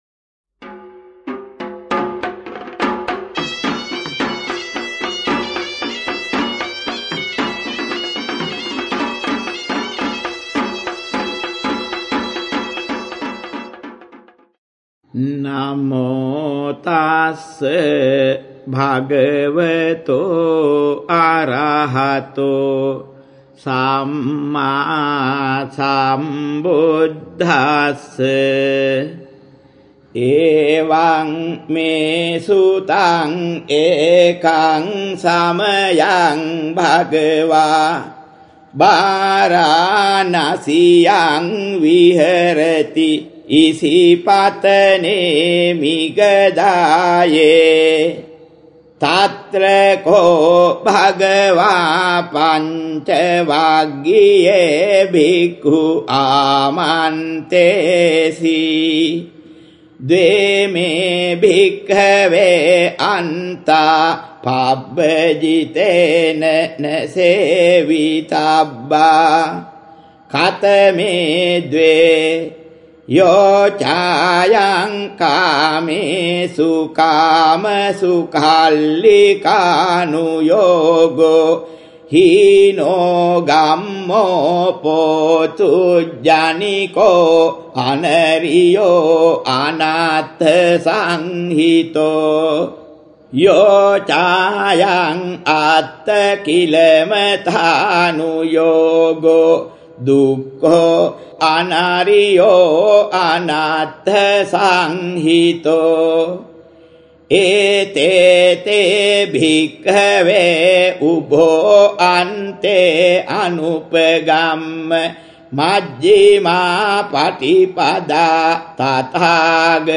▪ It may be helpful to listen to the chanting of the sutta by the venerable Thero , as it gives the correct pronunciations, and also how to chant it without “too much dragging” as done commonly (which will diminish its effects).